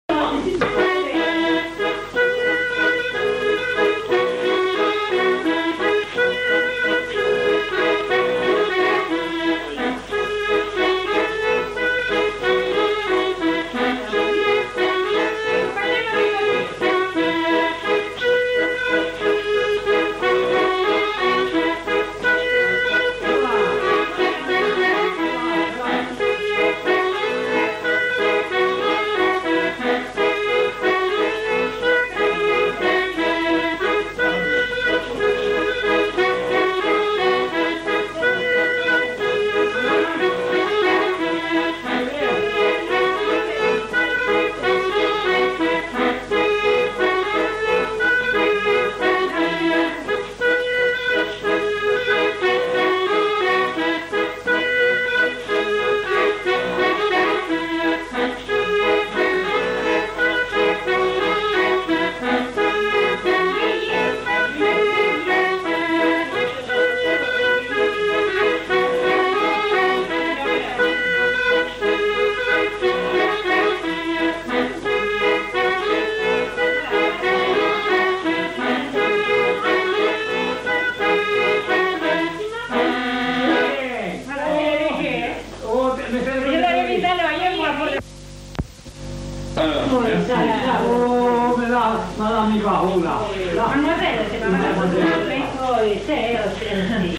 Répertoire de danses joué à l'accordéon diatonique
enquêtes sonores
Rondeau